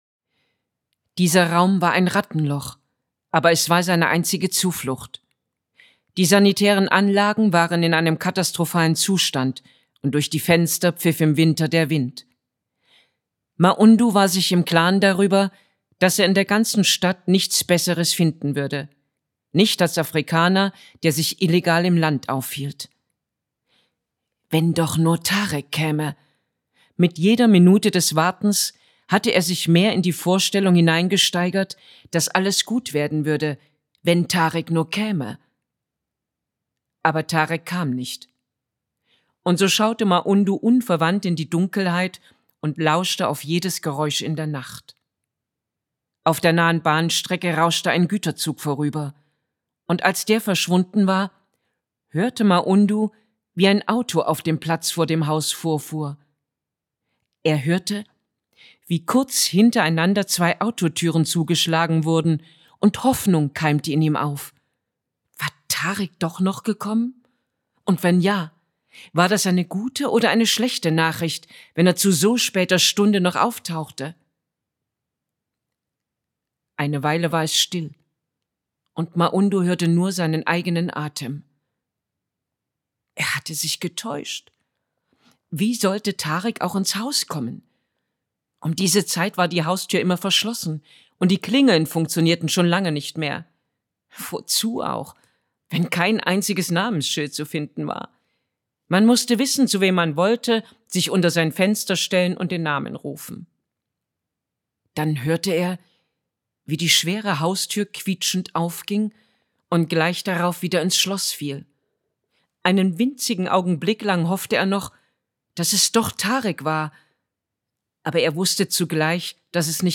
Hörprobe Schattenmensch: